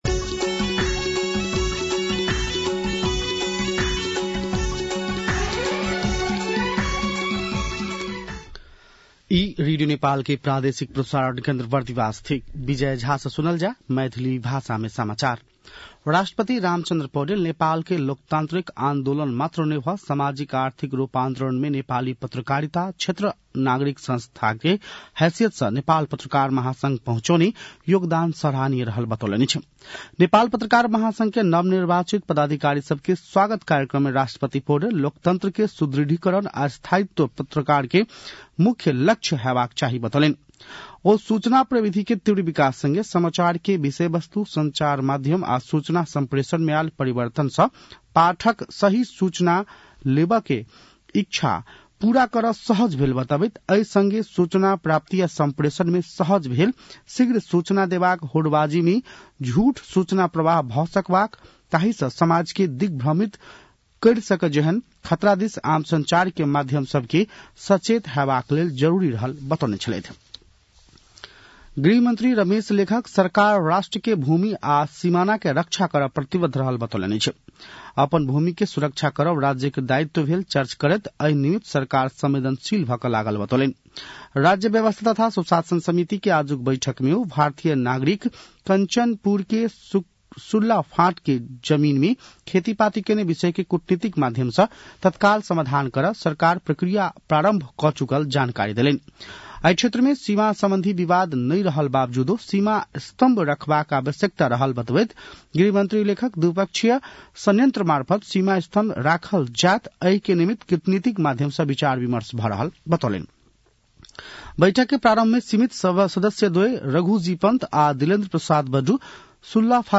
An online outlet of Nepal's national radio broadcaster
मैथिली भाषामा समाचार : २३ पुष , २०८१